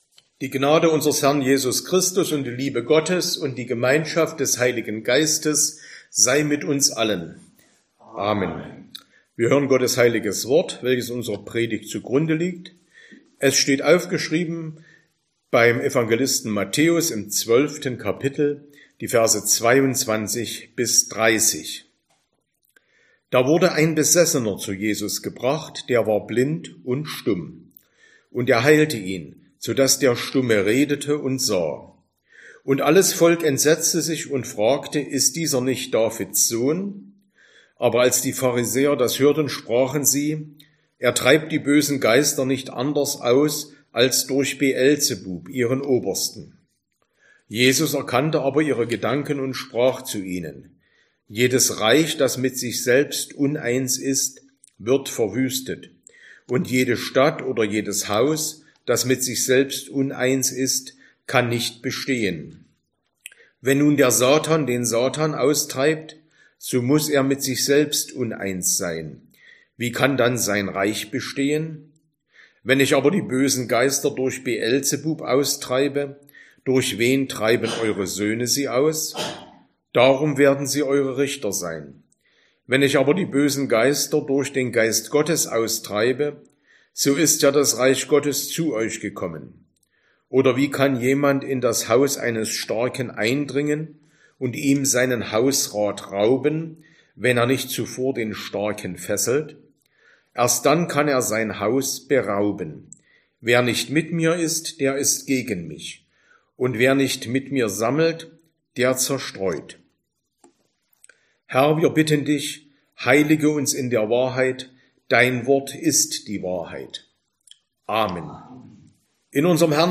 Sonntag nach Trinitatis Passage: Matthäus 12, 22-30 Verkündigungsart: Predigt « Erntedankfest 2024 8.